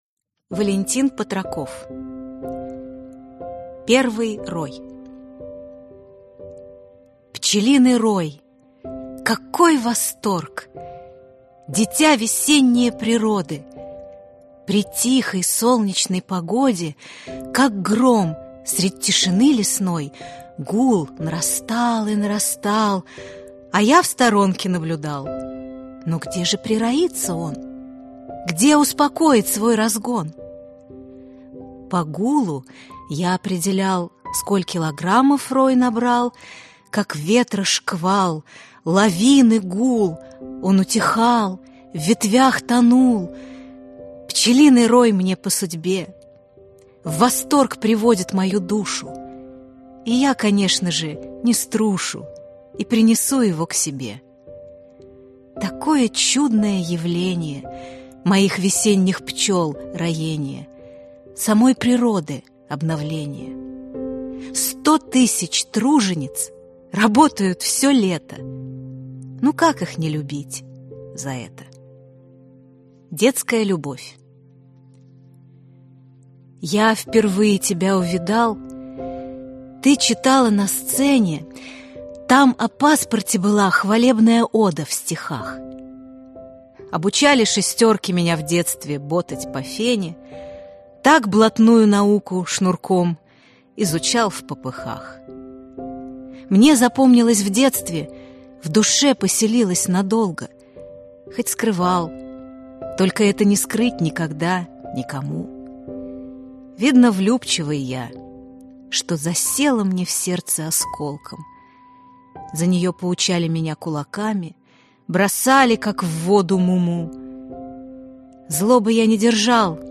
Аудиокнига Мой путь | Библиотека аудиокниг